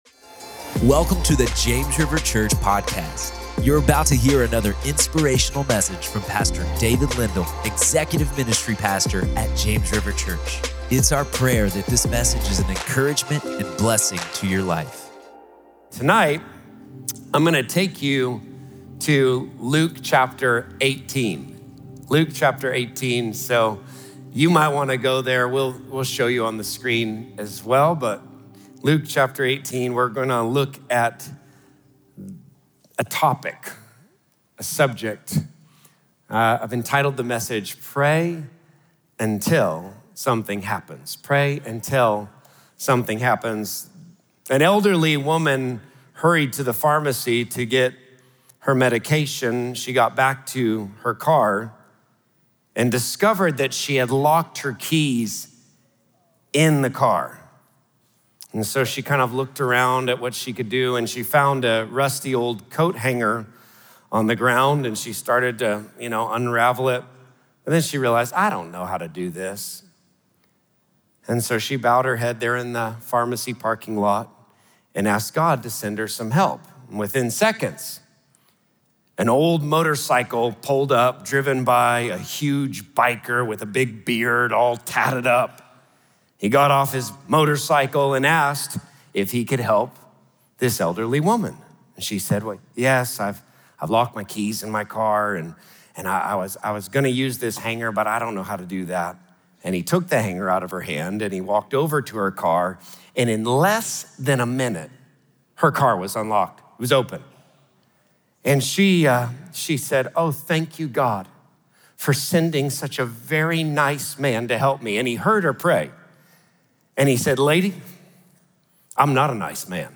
Pray Until Something Happens | Prayer Meeting